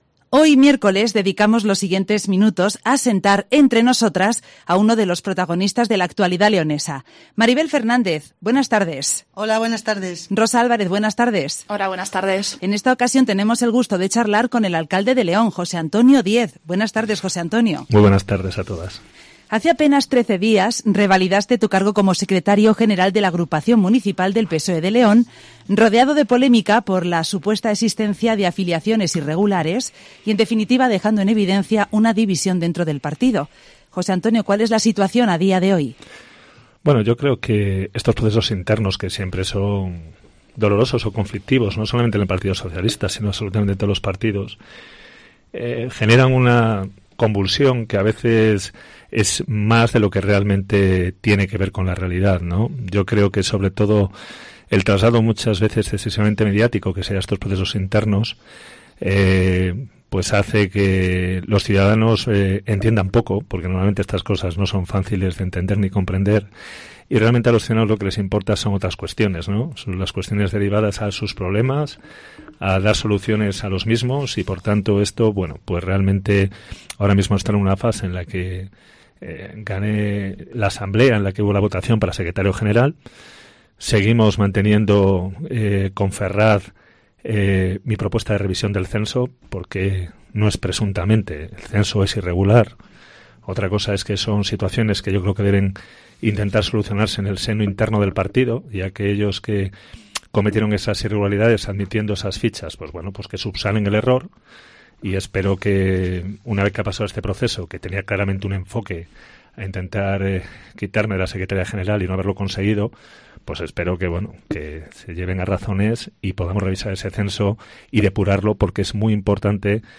Jose Antonio Diez, alcalde de León, ha participado hoy miércoles en el espacio “Entre Nosotras” de la cadena Cope para responder a la preguntas relacionadas con la división del PSOE en la provincia, su candidatura a la alcaldía en mayo de 2023 o la ruptura del pacto de gobernabilidad en la Diputación de León.